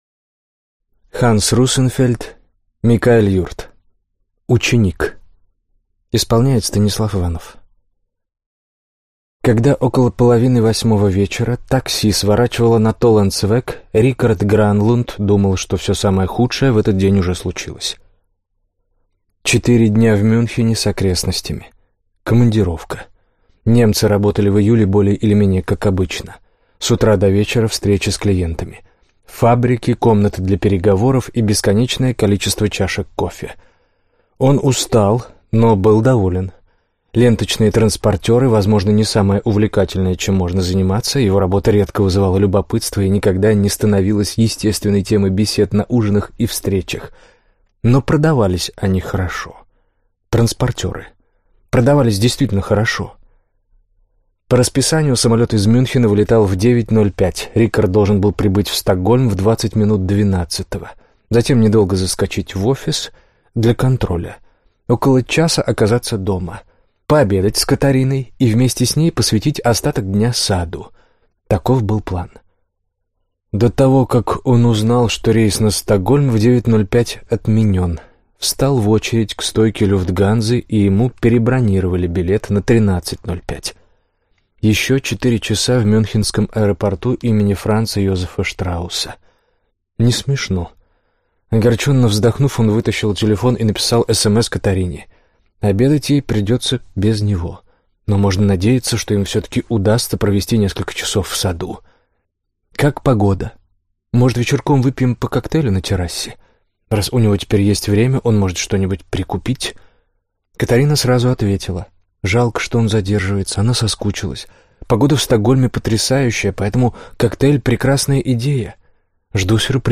Аудиокнига Ученик | Библиотека аудиокниг